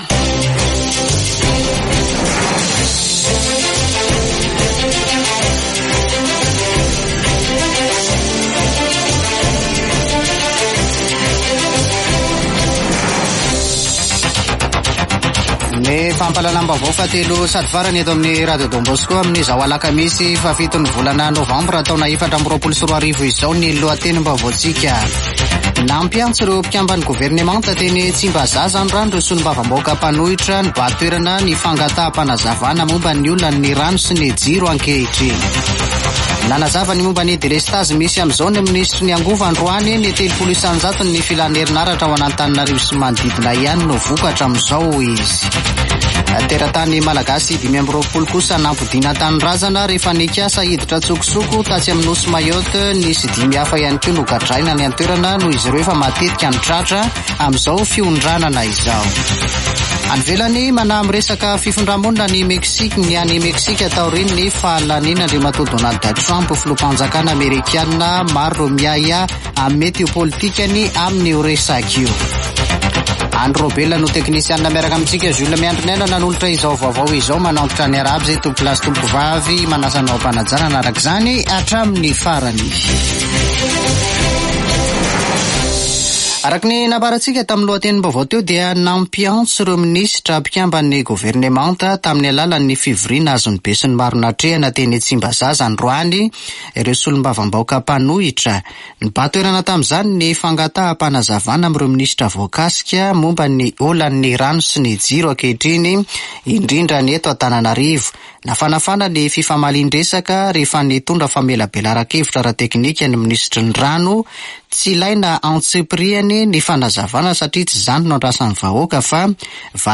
[Vaovao hariva] Alakamisy 7 nôvambra 2024